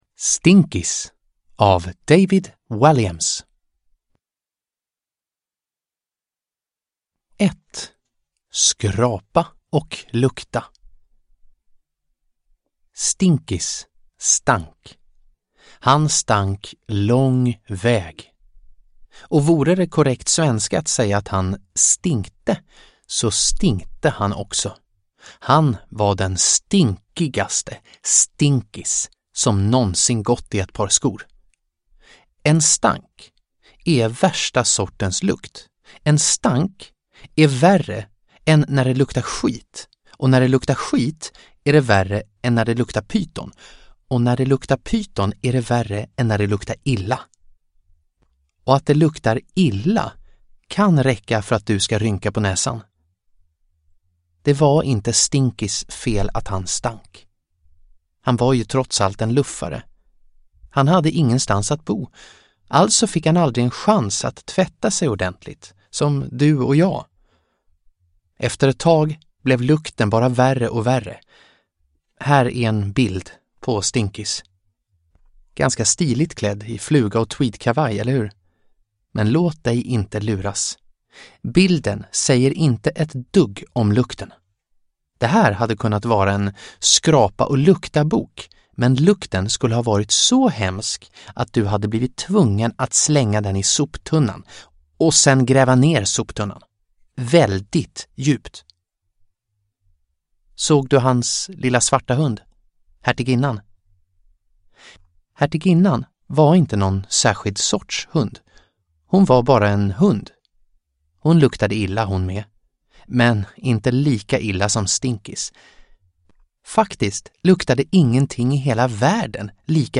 Stinkis – Ljudbok – Laddas ner